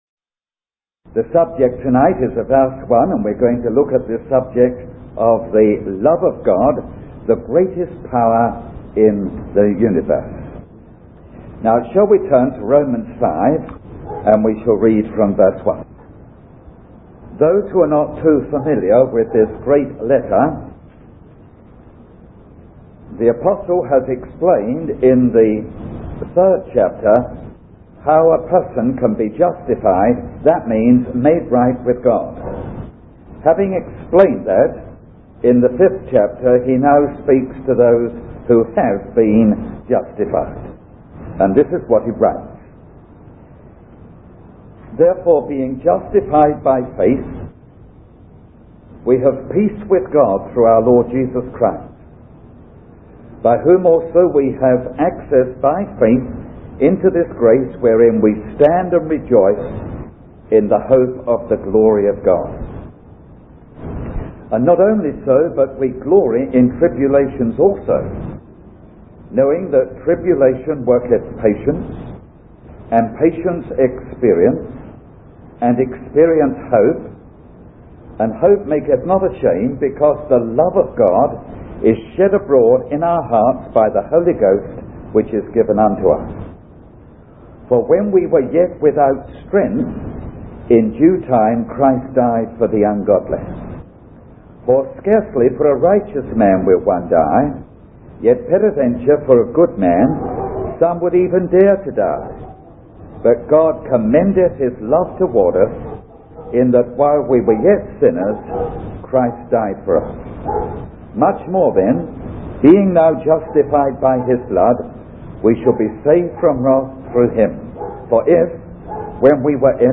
He encourages the congregation to open their hearts to this love, which is poured into us by the Holy Spirit, enabling us to reflect Christ's love in our lives.